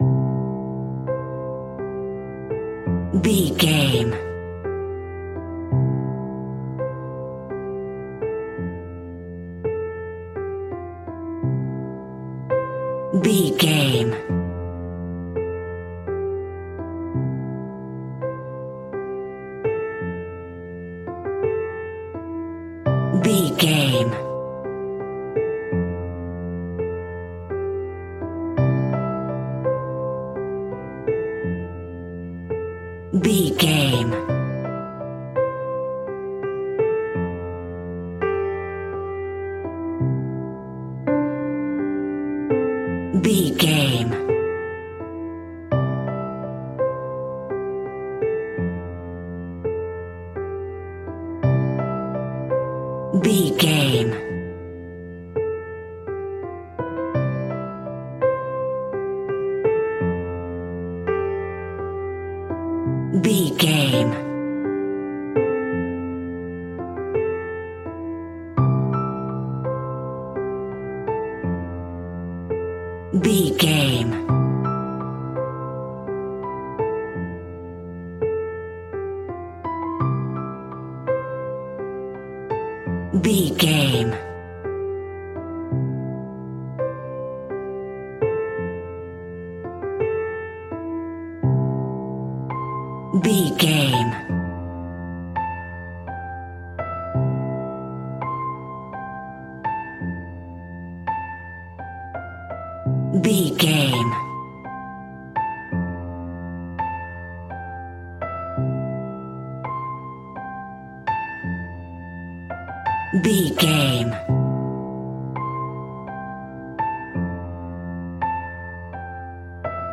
Soothing bright and happy piano music in a major key.
Regal and romantic, a classy piece of classical music.
Ionian/Major
soft